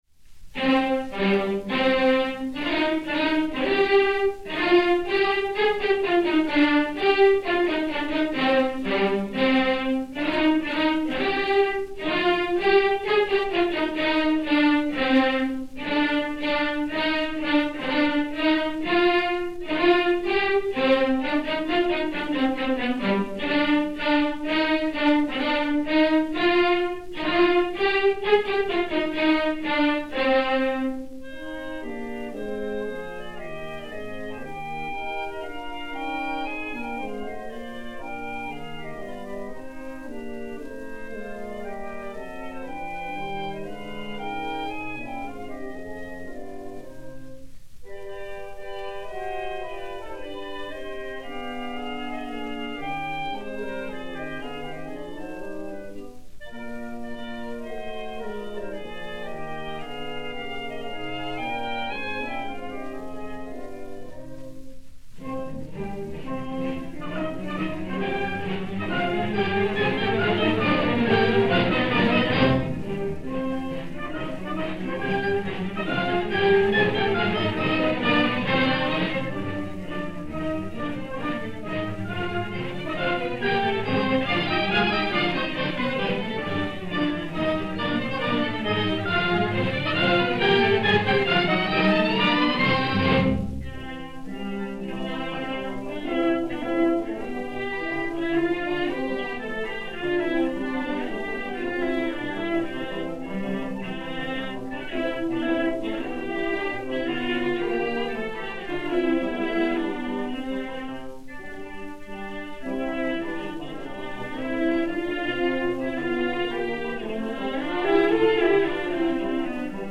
Orchestre Symphonique
Columbia D 6293, mat. L 622-1 et L 623-1, enr. le 14 décembre 1927